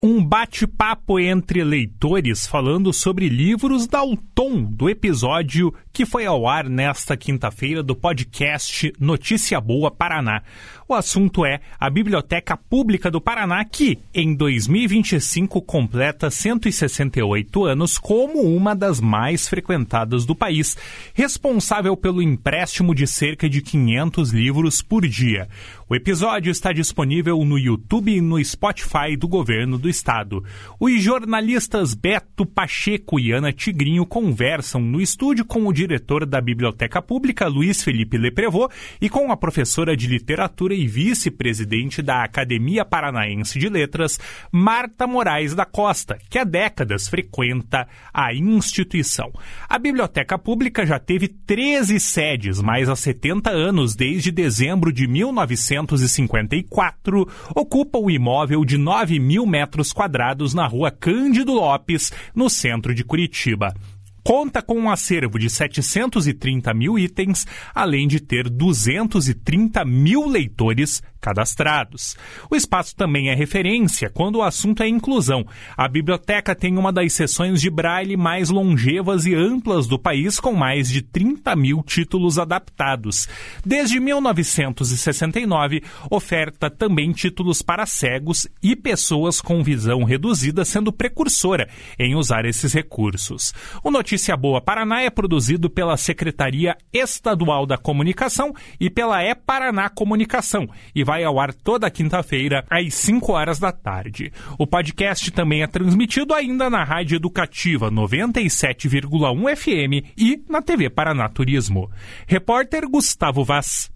Um bate-papo entre leitores, falando sobre livros, dá o tom do episódio que vai ao ar nesta quinta-feira do podcast Notícia Boa Paraná.